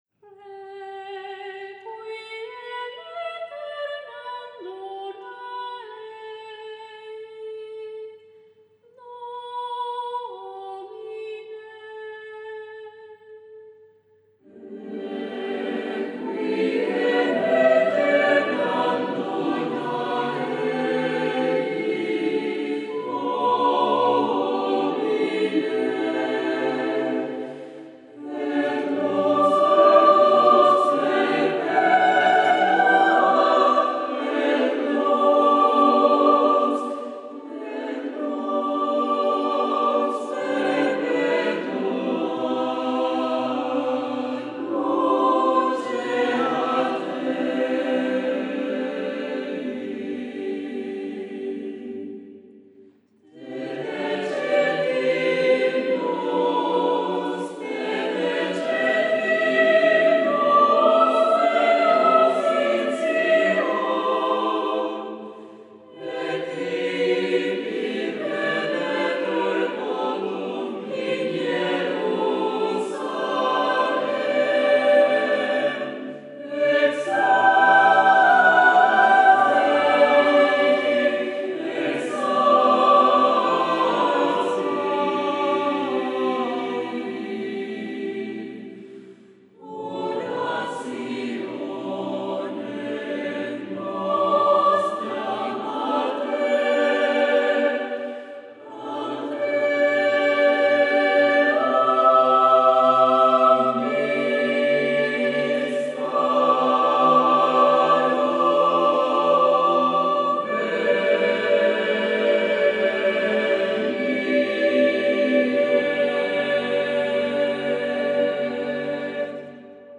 SSAATBB (7 voix mixtes) ; Partition complète.
Sacré ; Introït ; Hymne (sacré) ; Requiem
Solistes : Sopran (1) (1 soliste(s))
Tonalité : sol majeur